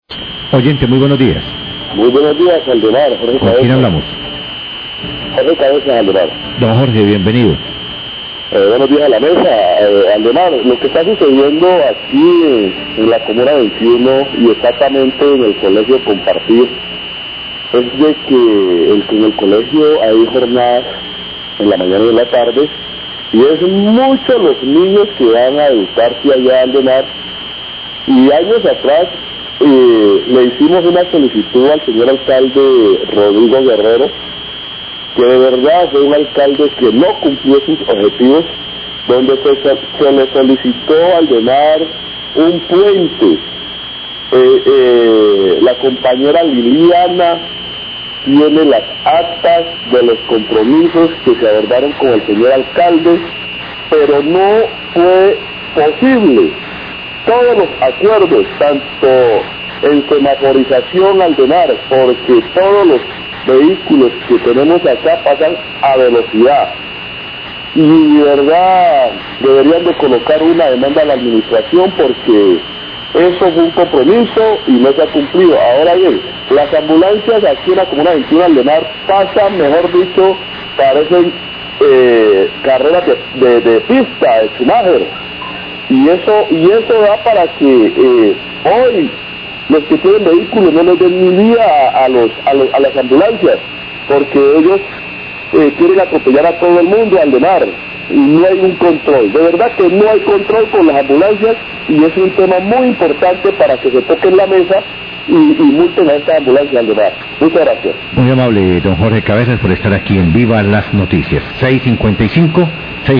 Radio
Oyente se queja por los compromiso incumplidos por parte de la alcaldía.